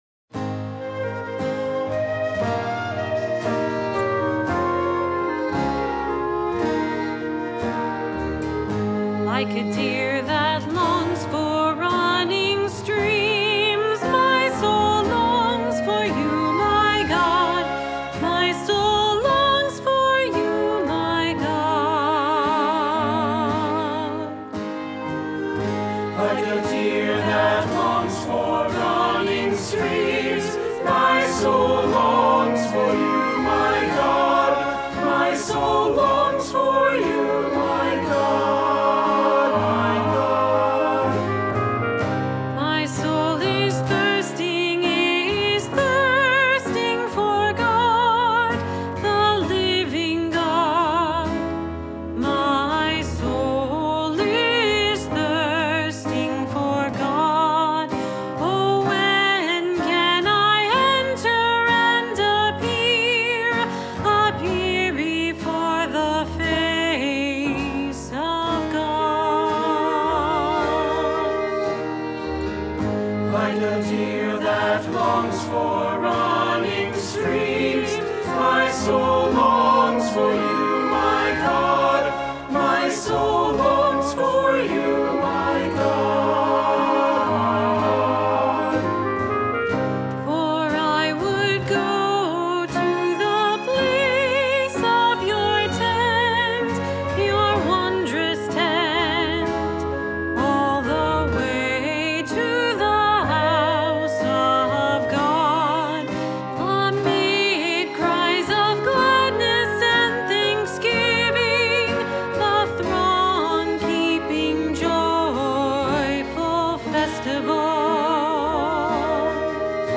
Voicing: "SAB","Cantor","Assembly"